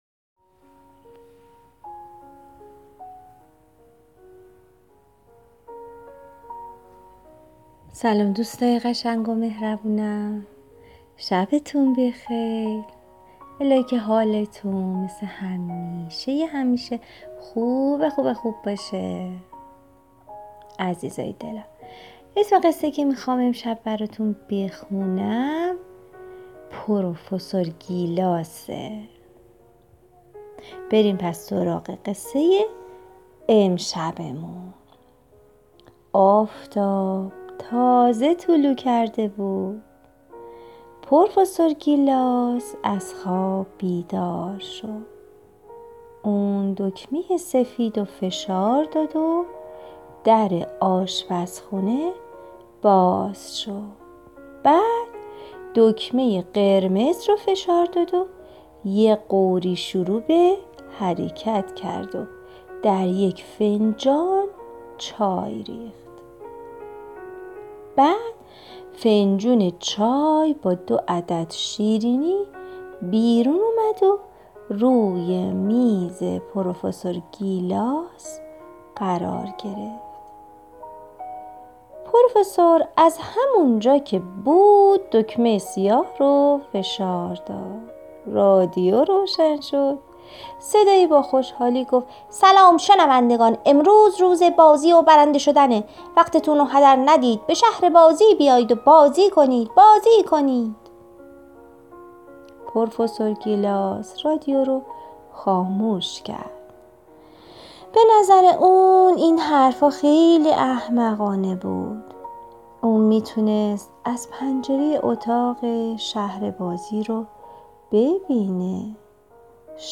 قصه صوتی کودکان دیدگاه شما 178 بازدید